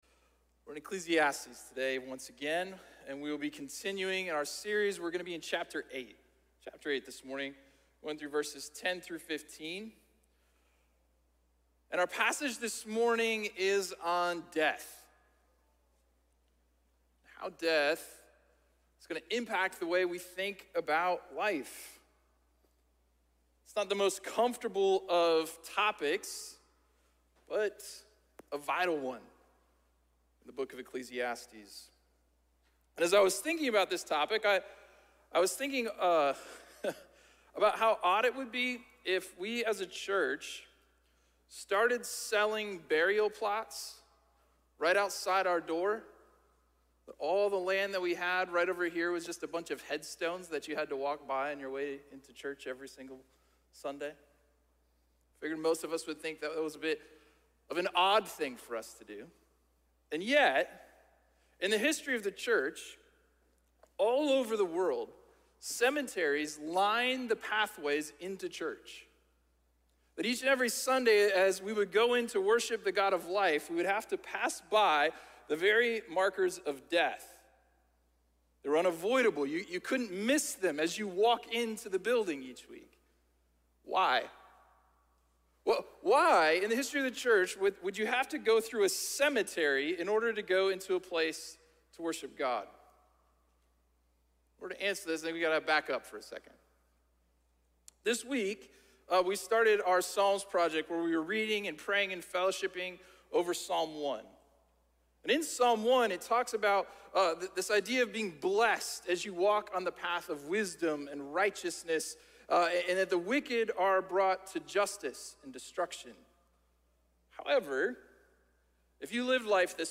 Sermon series through the book of Ecclesiastes.